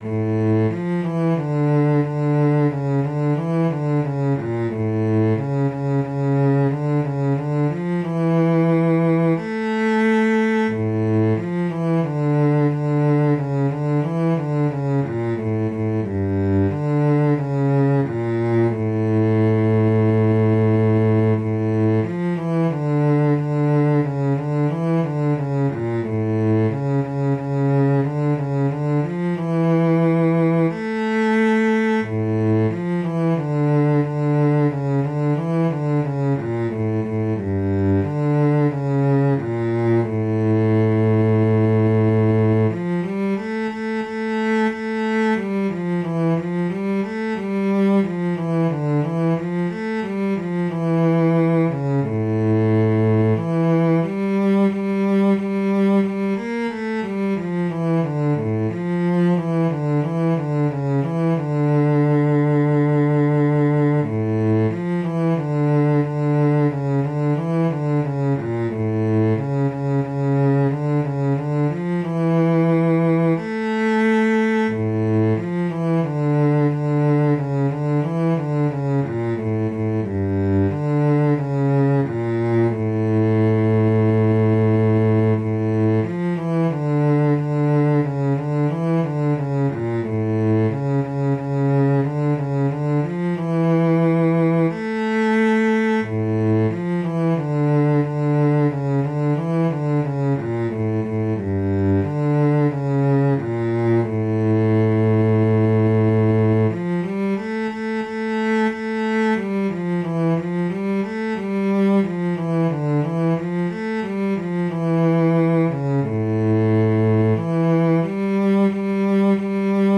Jewish Folk Song
D minor ♩= 90 bpm